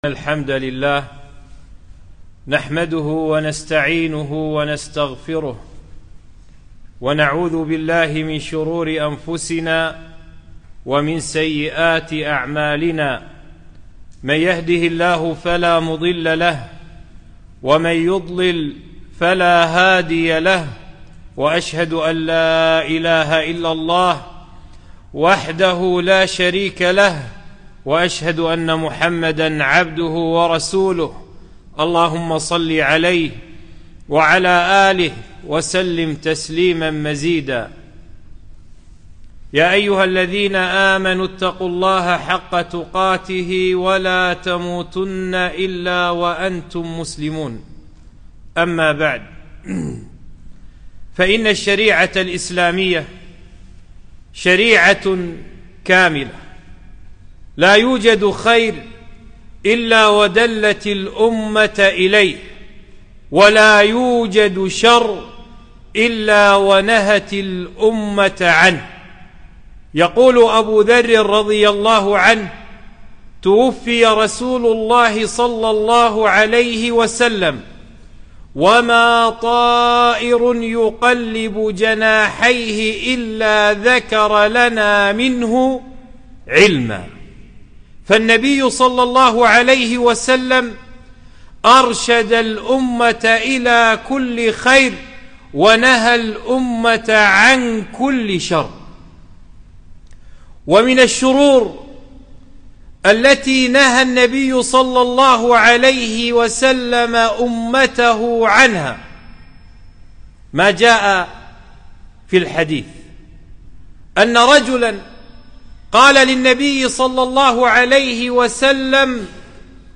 خطبة - لا تغضب